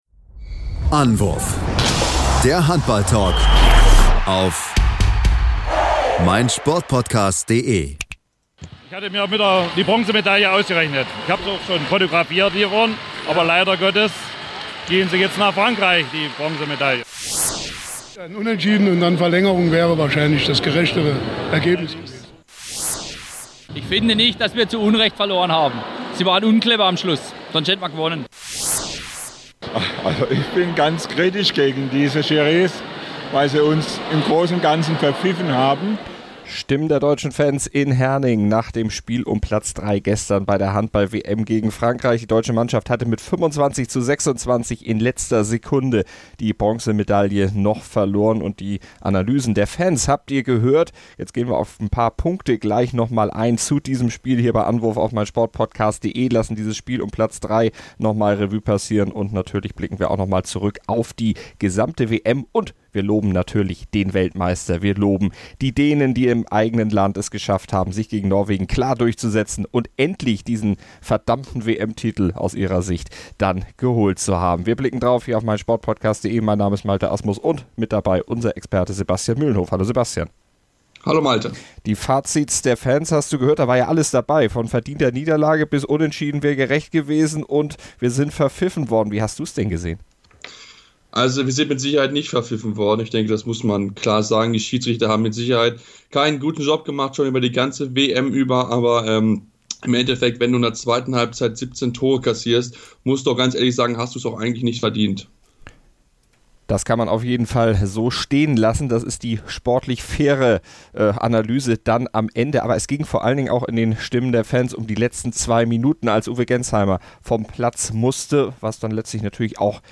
präsentieren euch die Stimmen von vor Ort
Uwe Gensheimer, Matthias Musche, Patrick Grötzki und Co. analysieren ihren Auftritt auf der Platte selbst. Auch der Weltmeistertrainer kommt zu Wort.